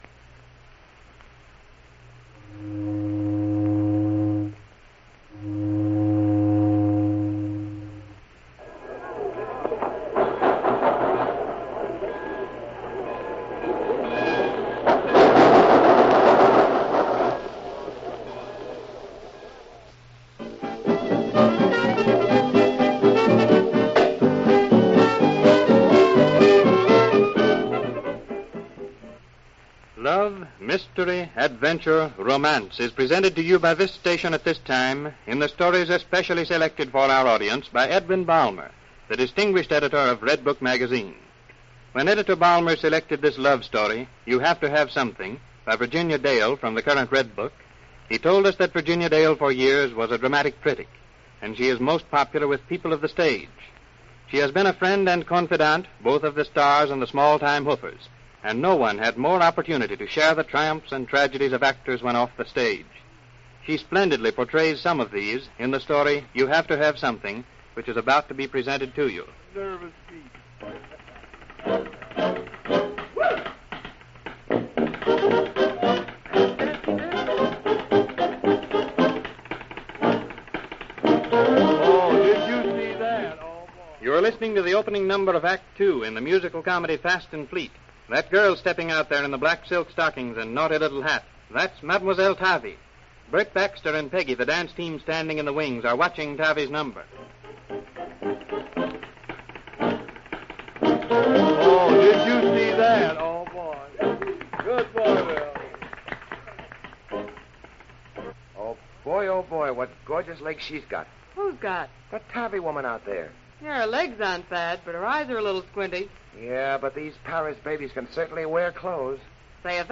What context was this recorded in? "Redbook Dramas" was a beloved radio series in the early 1930s that brought short stories from Redbook Magazine straight into the living rooms of listeners eager for captivating tales.